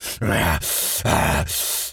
tas_devil_cartoon_15.wav